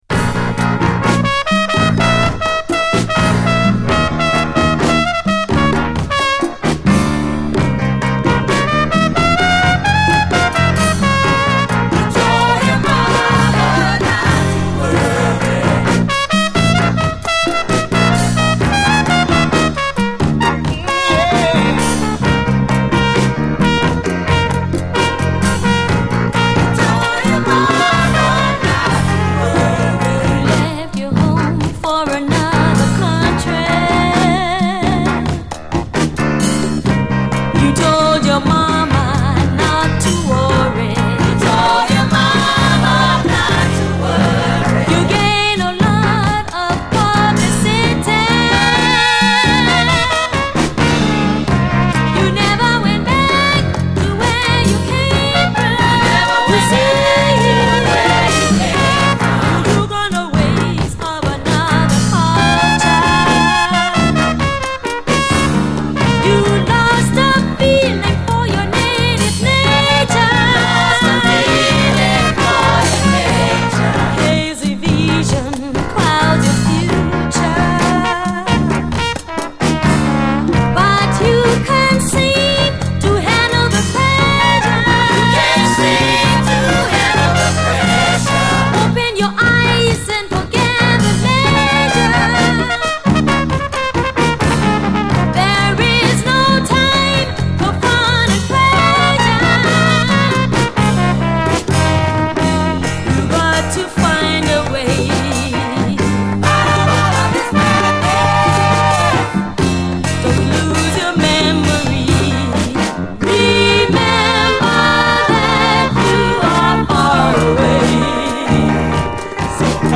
SOUL, 70's～ SOUL, AFRO, WORLD
真っ黒いグルーヴと、女性ヴォーカル＆コーラスの醸し出す雰囲気がヤバいです。